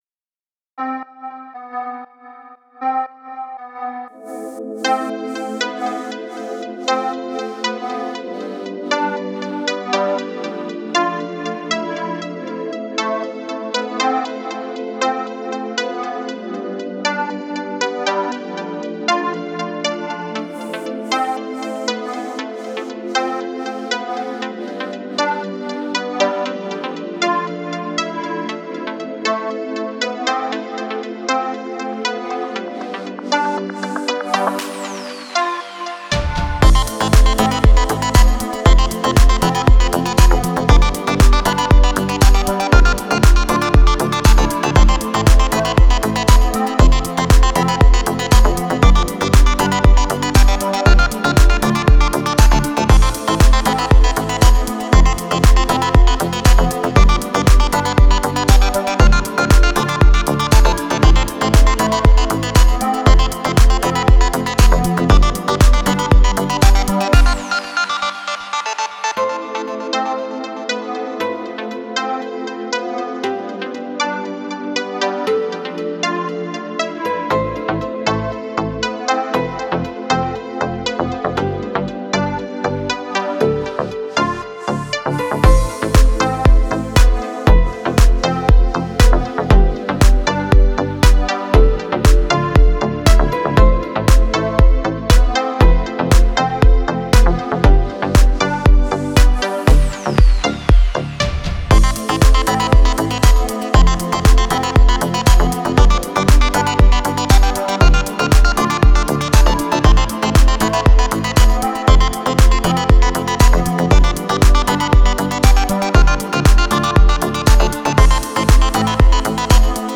Deep House
Deep House без слов